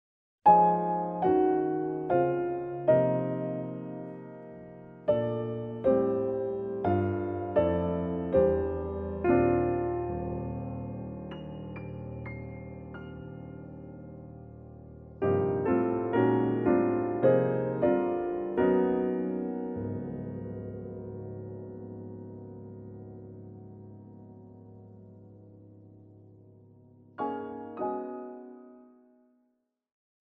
piano solo -